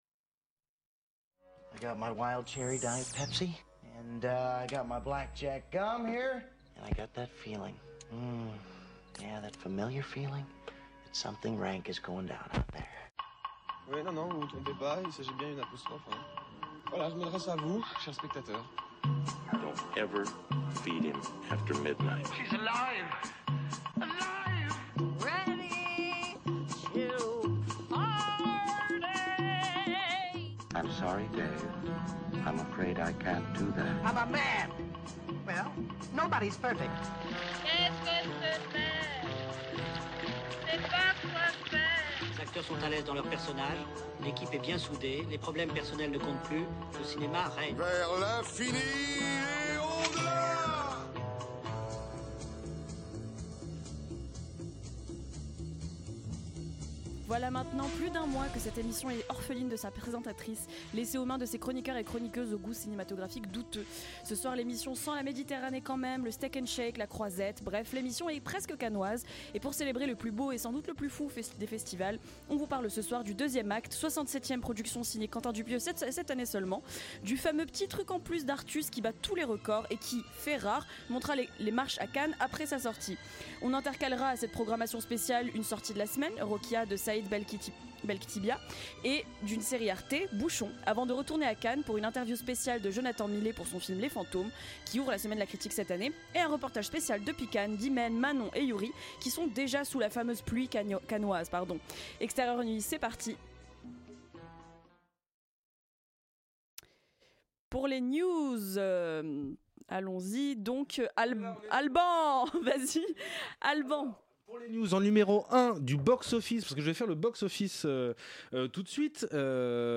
En parallèle, notre équipe envoyée spéciale au 77ème Festival de Cannes nous parle du premier film de la Compétition Officielle : DIAMANT BRUT, d'Agathe Riedinger.